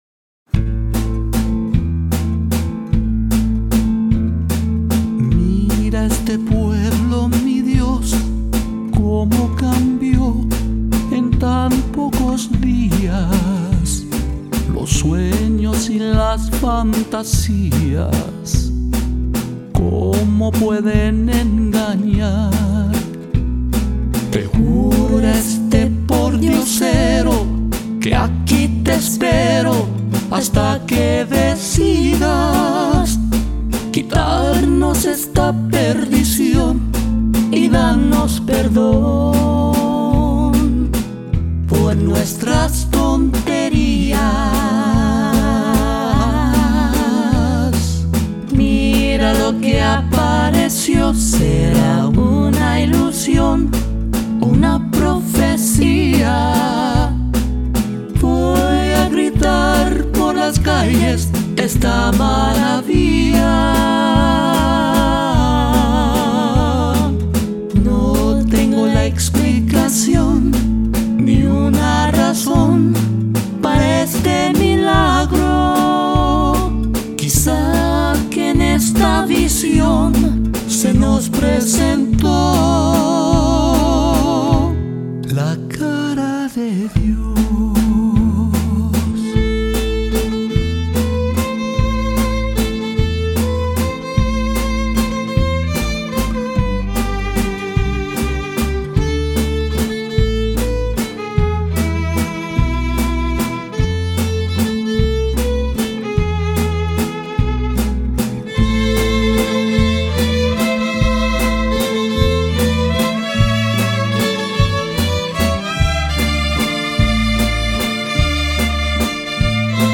ethnic band